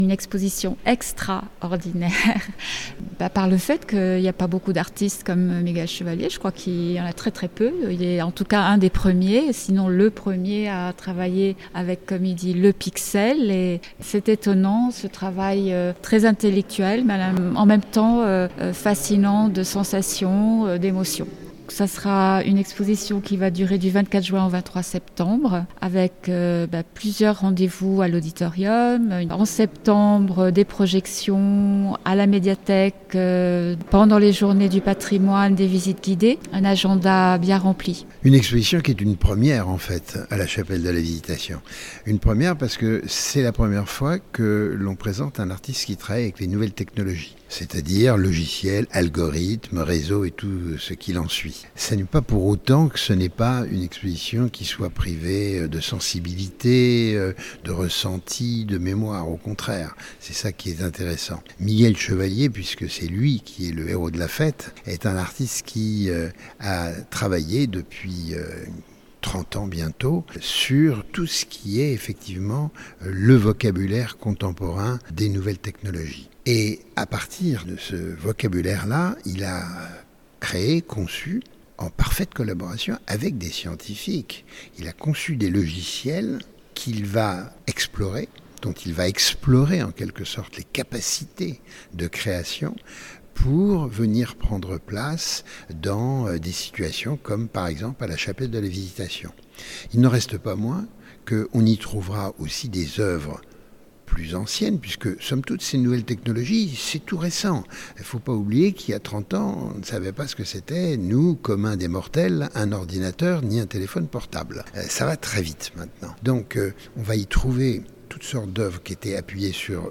Miguel Chevalier, Digital Cosmologies , la nouvelle exposition de La Chapelle de la Visitation à Thonon (interviews)